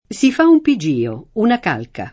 pigio [ pi J& o ] s. m.